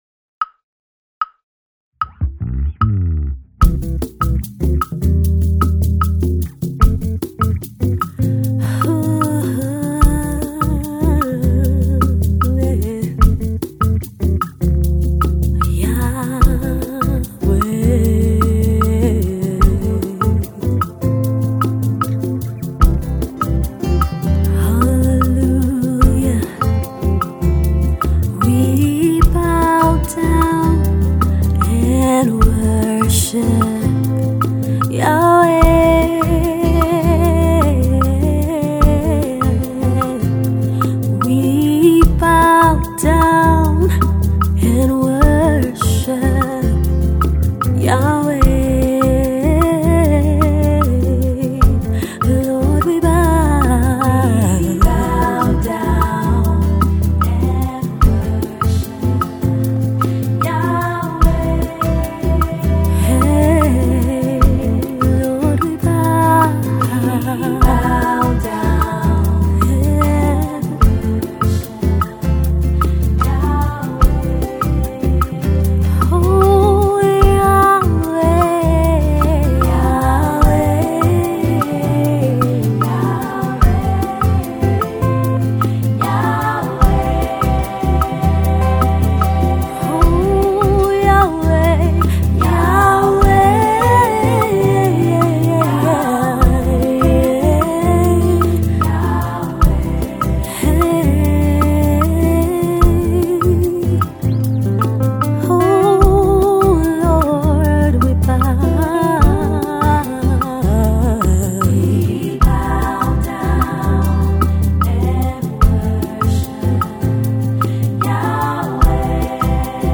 Soul Singer
Bass Guitarist
a soulful Afro Jazz version of the popular worship song